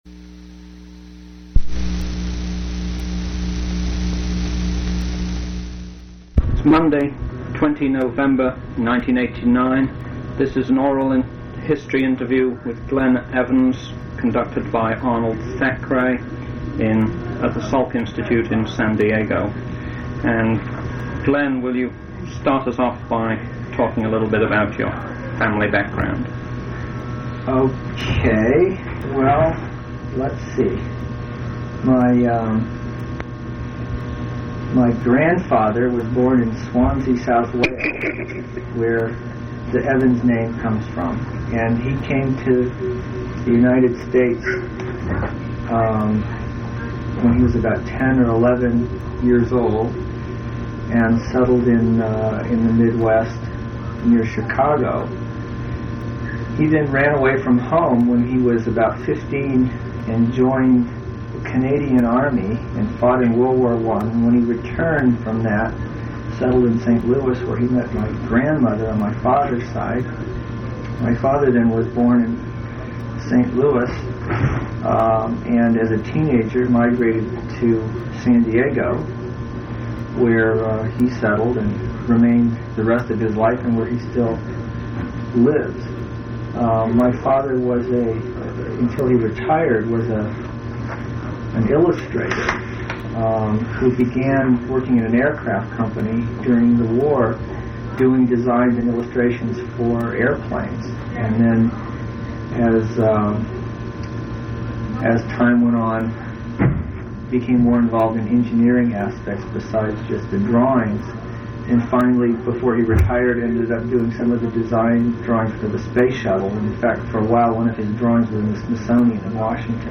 Place of interview Salk Institute for Biological Studies California--San Diego
Genre Oral histories